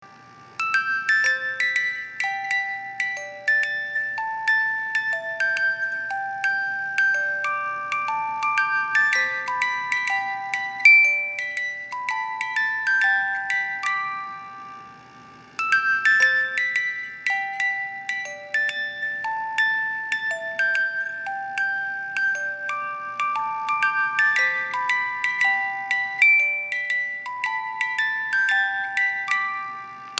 因為機械設計的限制，十八音梳機芯，只有十五秒左右的旋律，上發條後，這十五秒左右的旋律會重覆的撥放，直到發條鬆了為止！
機芯轉動時皆會有運轉聲、金屬磨擦聲(電動機芯也會有馬達運轉聲)，請知悉。